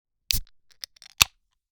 SodaTestSFX.mp3